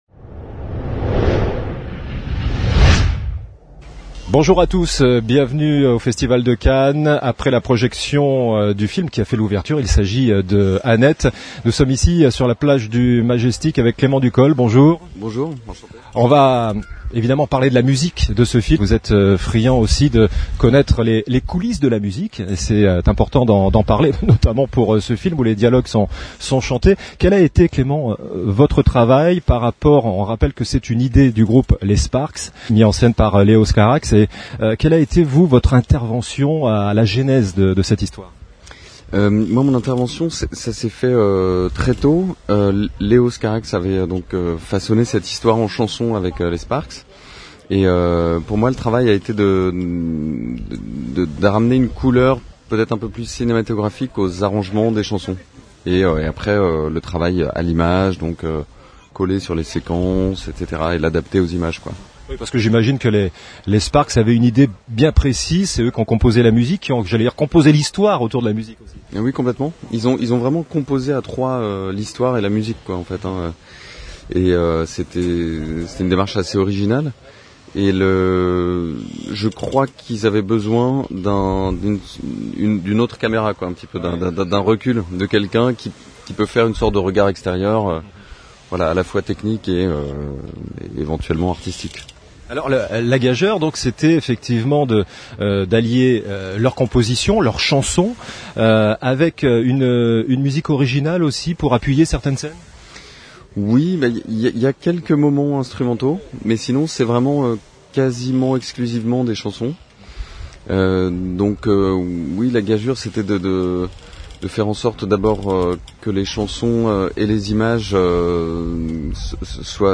2. Podcasts cinéma : interviews | La Radio du Cinéma
Une interview organisée dans le cadre des temps forts SACEM au festival de cannes.